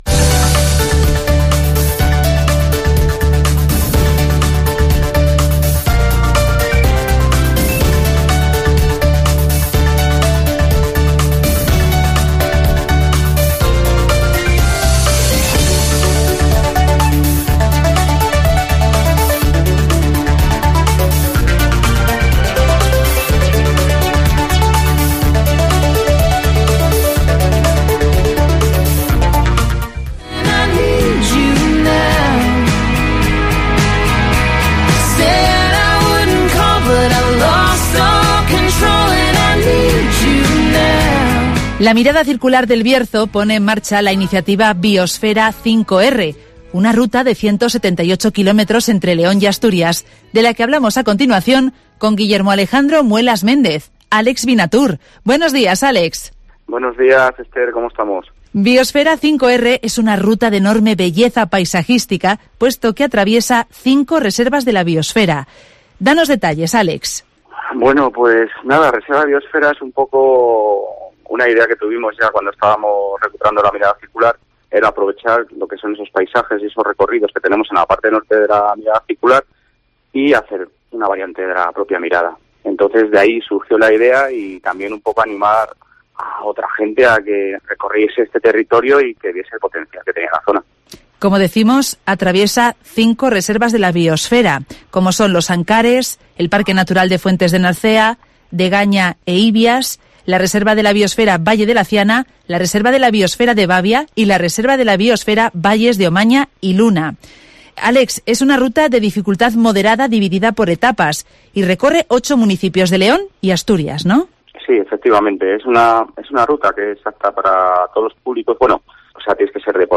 La Mirada Circular del Bierzo pone en marcha 'Biosfera 5R' que recorre 178 kilómetros de León y Asturias (Entrevista